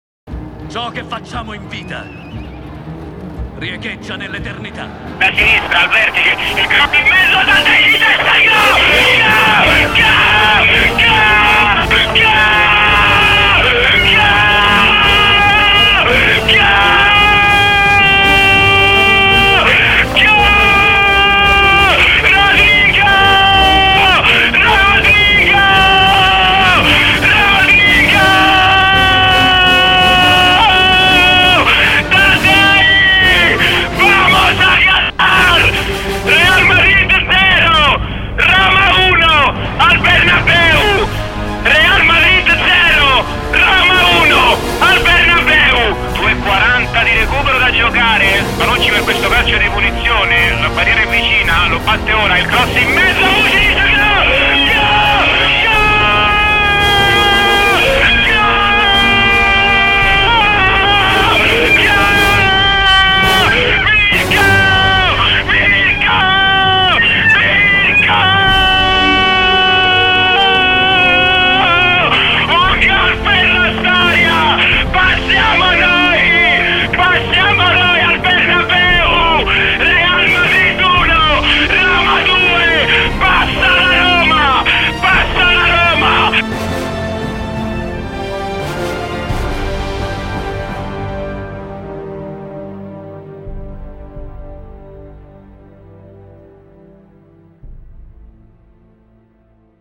radio romanista ai gol della Roma (3120 kb, MP3)